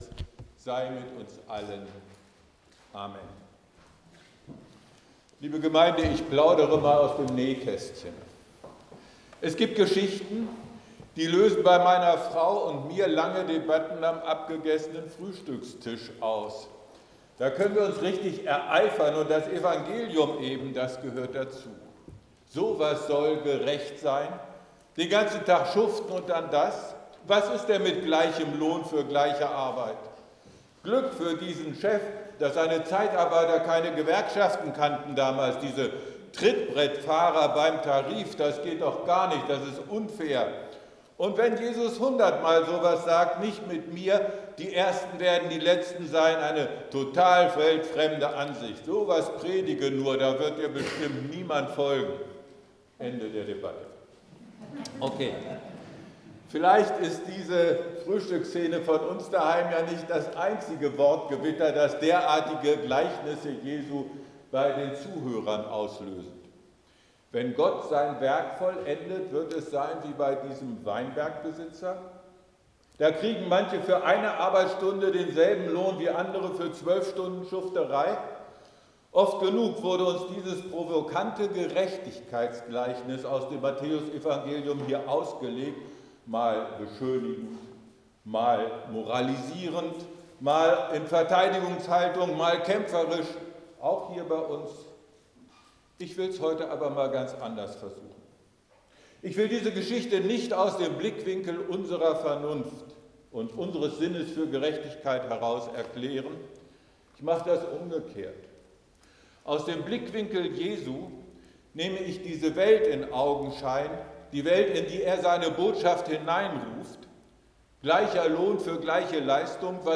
Gottesdienst+vom+25.07.mp3